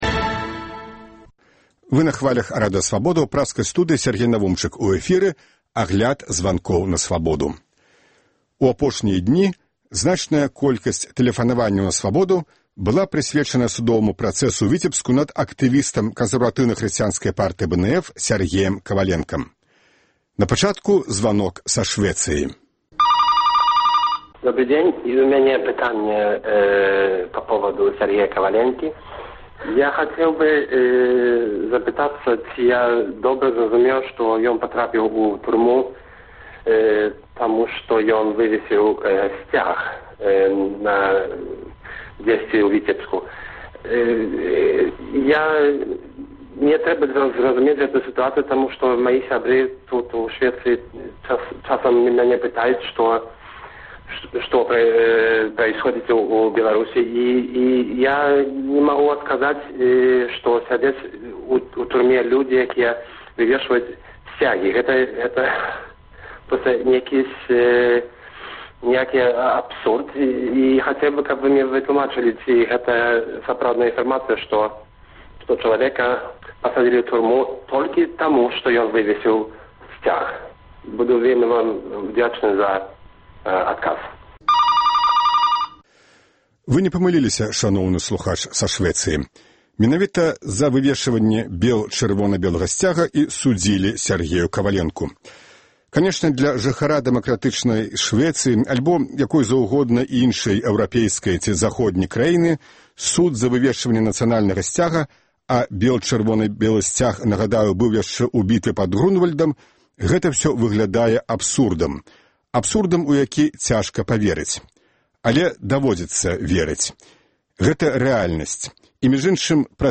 Агляд тэлефанаваньняў радыёслухачоў